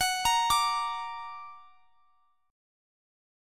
Listen to Gb+ strummed